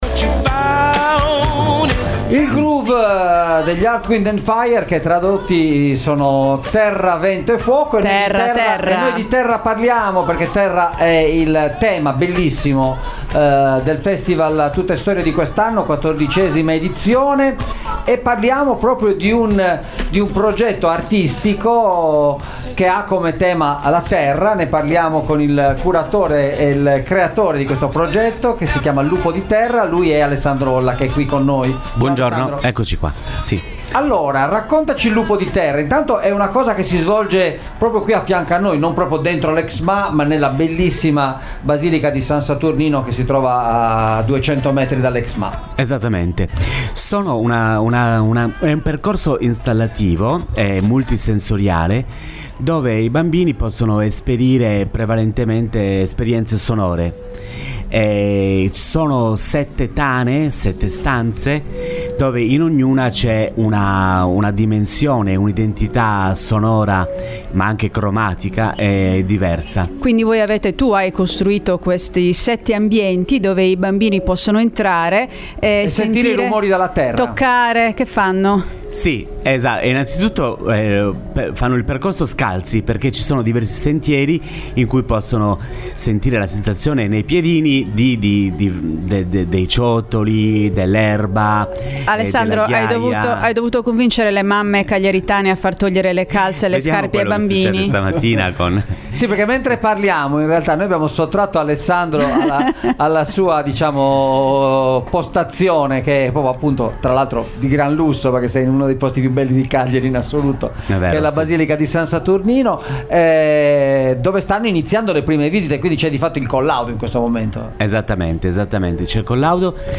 In diretta dal Festival Tuttestorie!